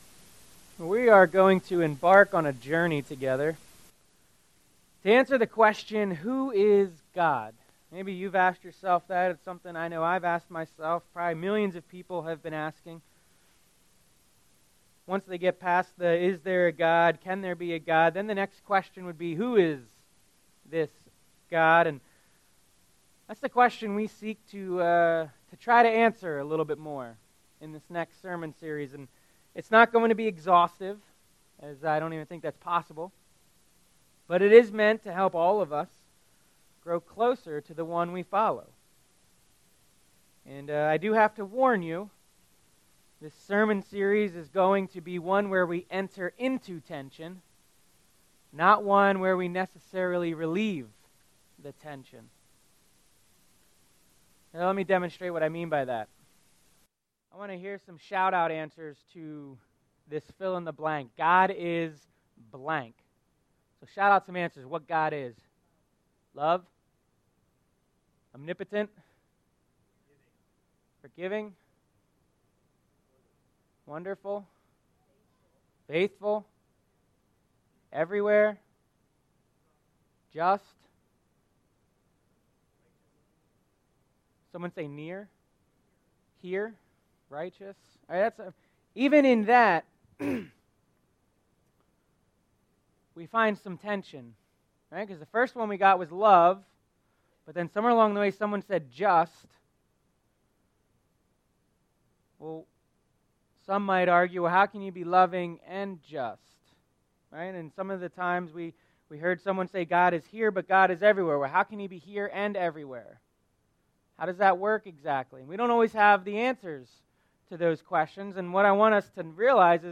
Sermon Series - July 3 - Bigger July 10 - The Perfect Judge July 17 - The Perfect Parent July 24 - The Perfect Friend July 31 - Our Story